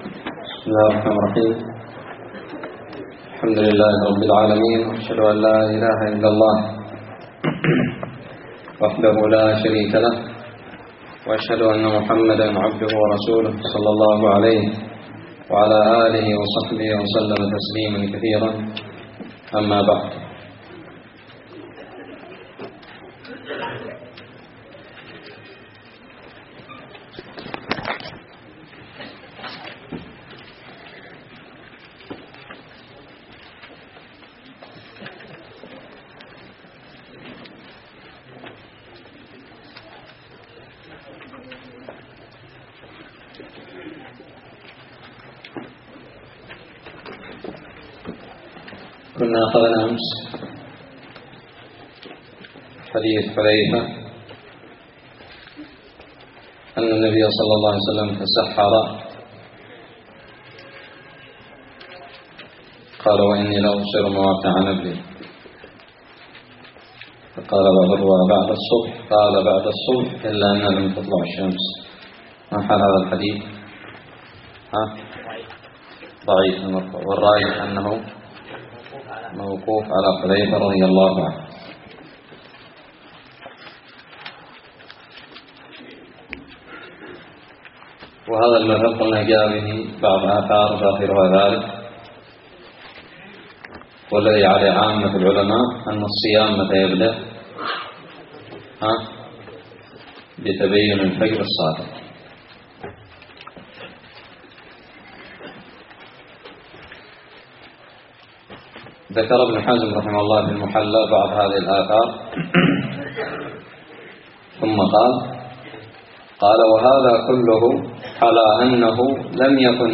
الدرس السادس والعشرون من كتاب الصيام من الدراري
ألقيت بدار الحديث السلفية للعلوم الشرعية بالضالع